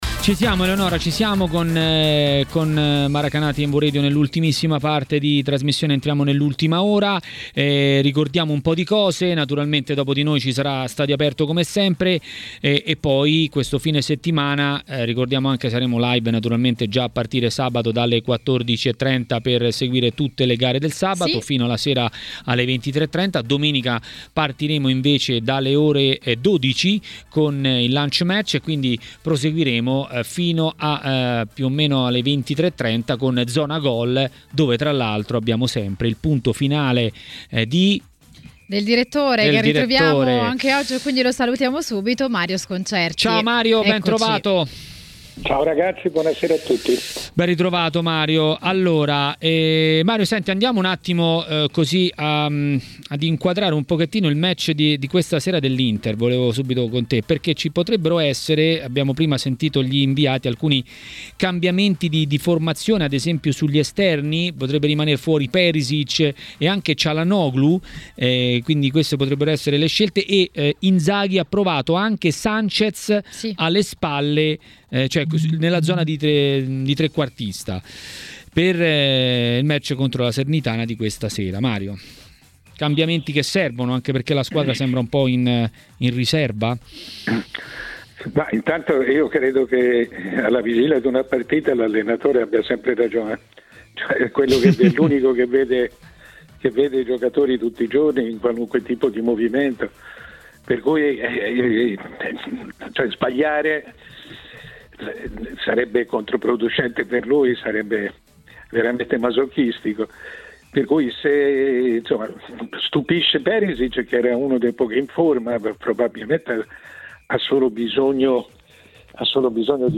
A Maracanà, ieri nel pomeriggio di TMW Radio, il direttore Mario Sconcerti si è soffermato sui due match principali del 28° turno